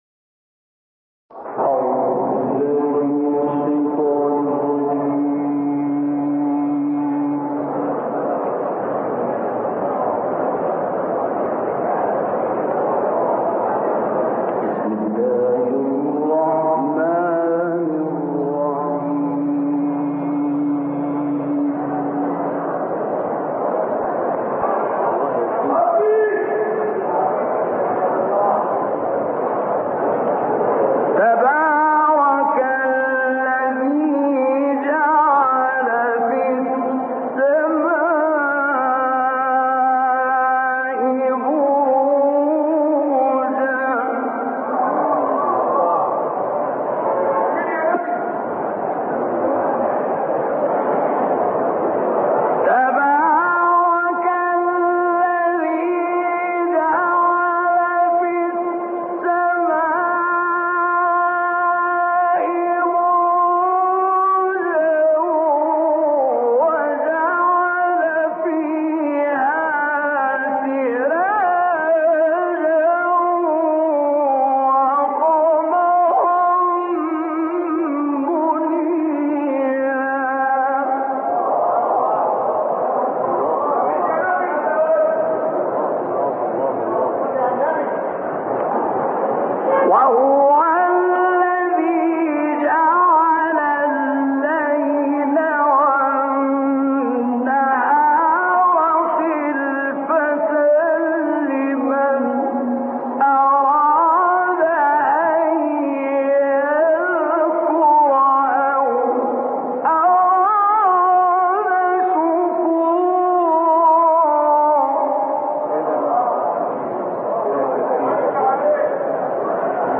تلاوت کوتاه مجلسی حمدی زامل از آیات 61 تا 66 سوره فرقان به مدت 4 دقیقه و 29 ثانیه